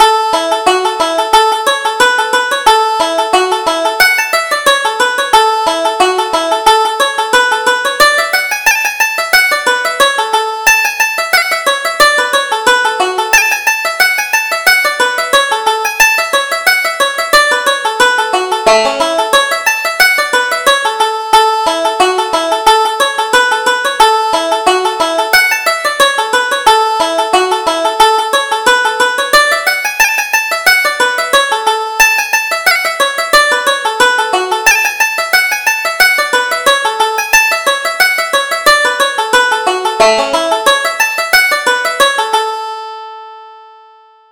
Reel: Are You Willing?